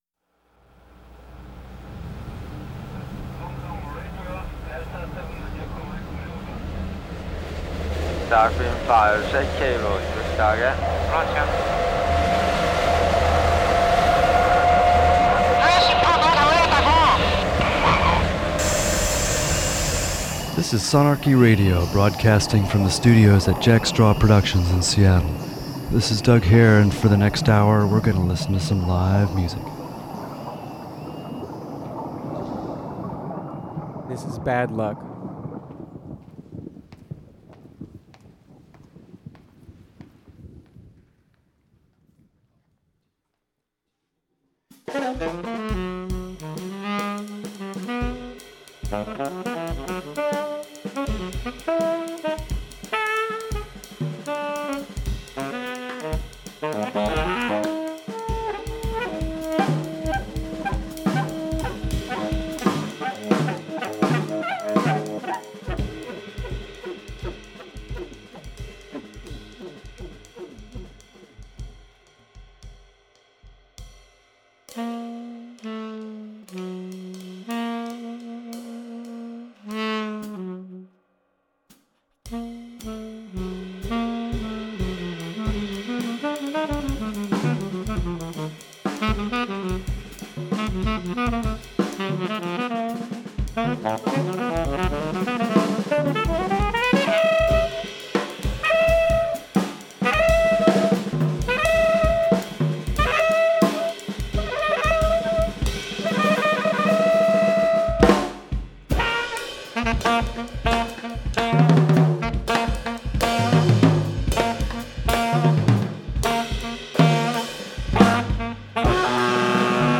Modular analog and digital synthesis.